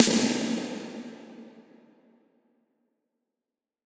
Explosion 15 Reverb.wav